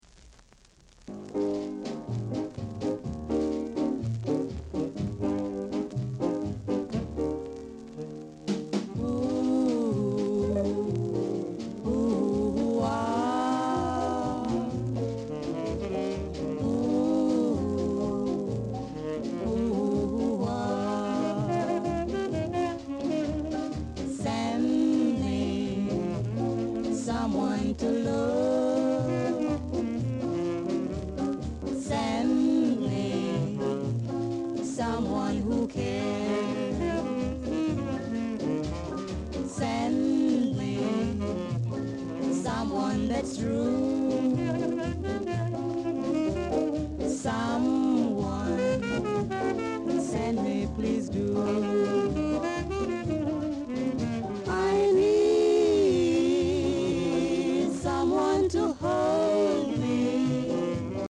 7inch / Ja / Ska Inst, Vocal Duet /